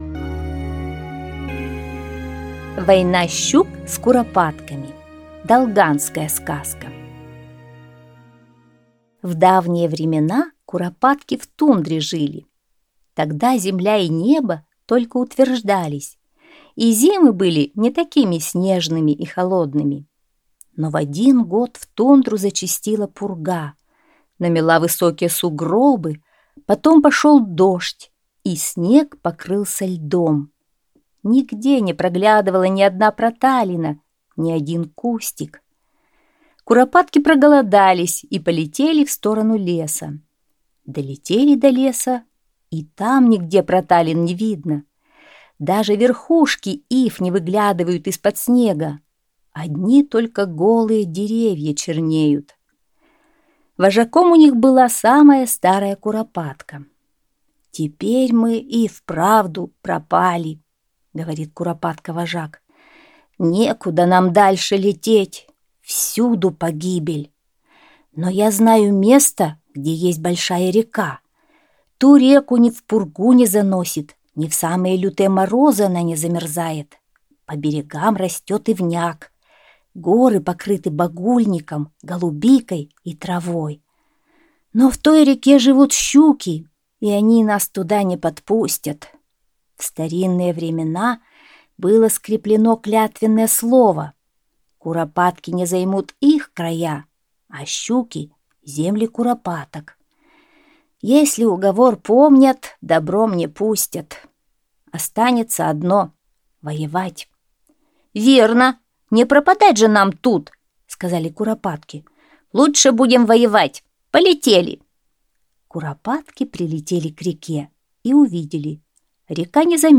Долганская аудиосказка